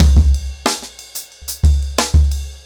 InDaHouse-90BPM.19.wav